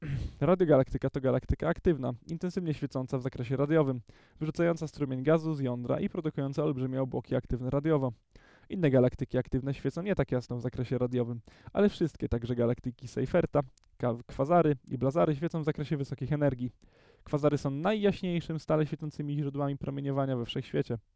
Male: Reading
Mężczyzna: Czytanie
ID053_reading.wav